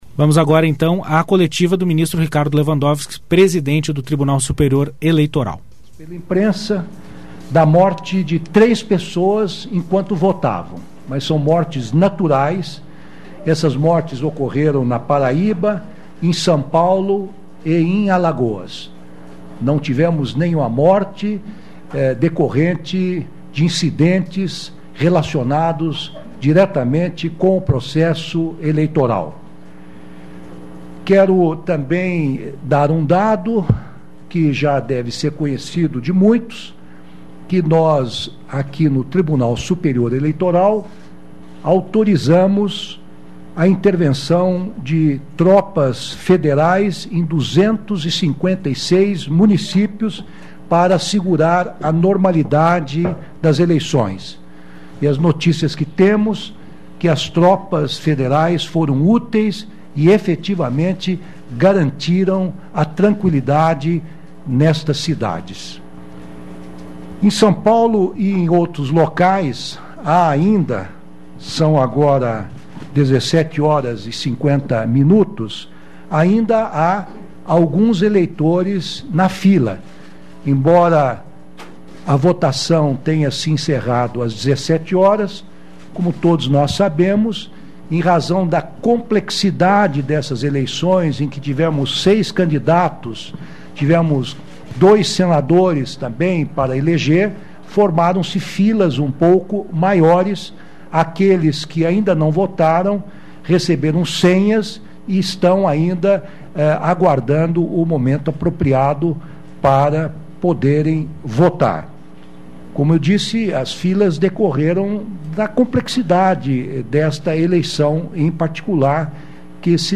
Coletiva com o ministro Ricardo Lewandowski